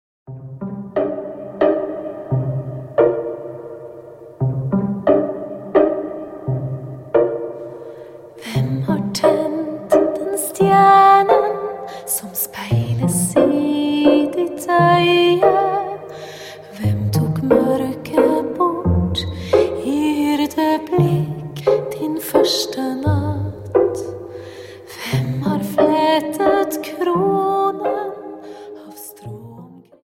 Dance: Slow Waltz 28 Song